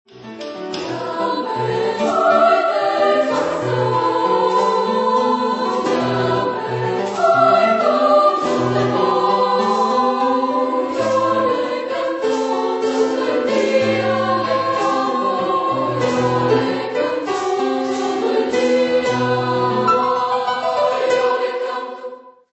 Genre-Style-Forme : Profane ; Folklore ; Latino-américain
Caractère de la pièce : énergique ; agressif ; expressif
Instruments : Piano (1)
Tonalité : mi mineur